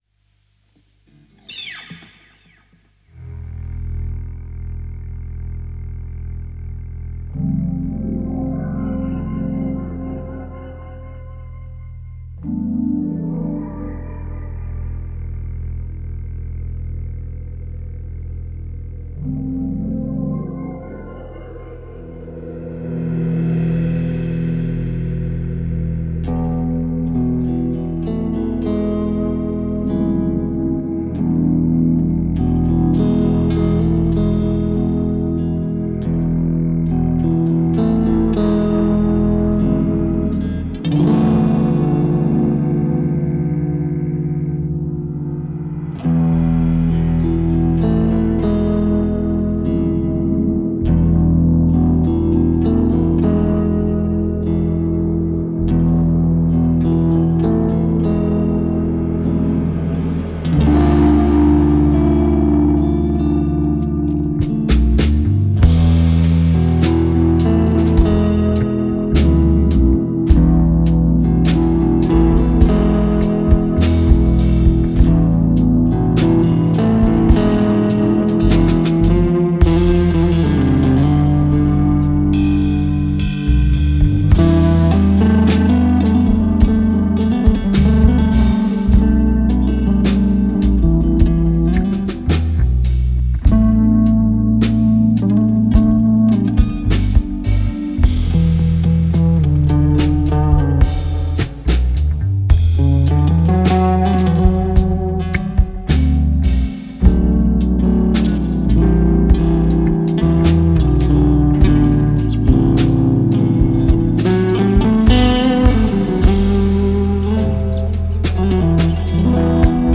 met live recorded jam by 'Zolder'